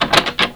locked_door1.wav